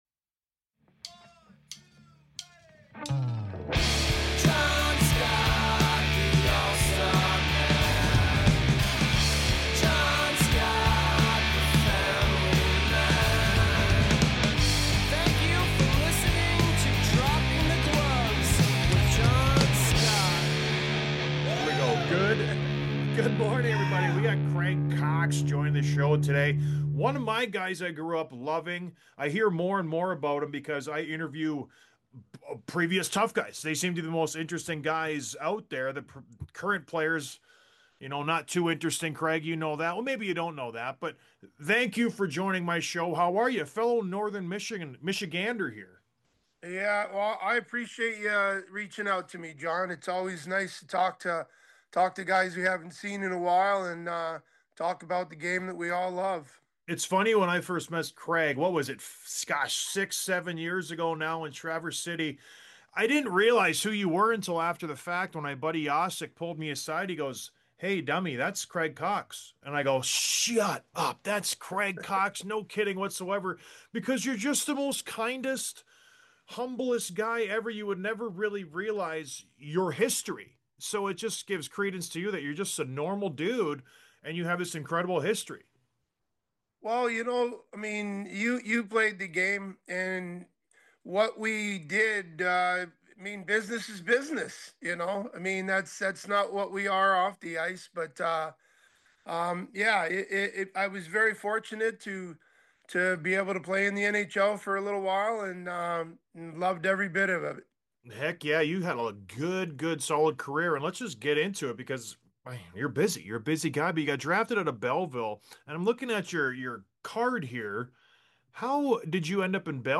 Interview w